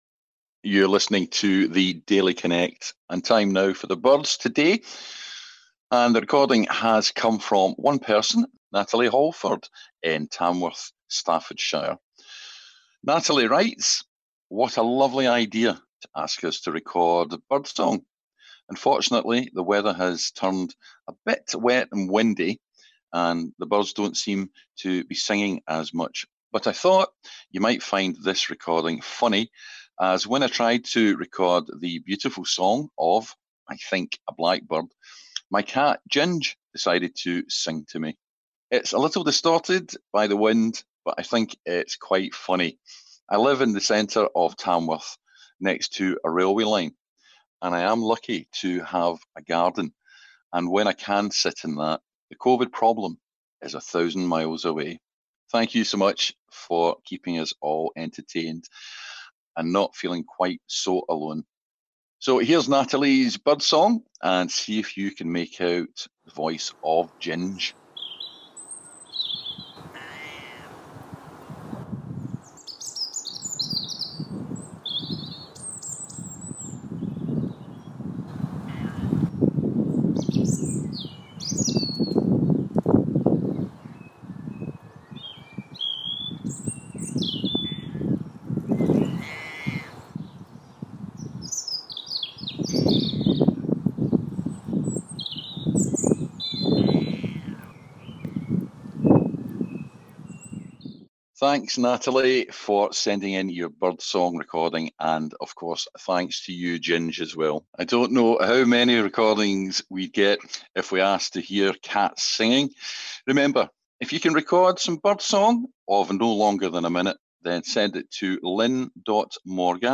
On RNIB Connect Radio, we're asking you to send in recordings of the birds you can hear.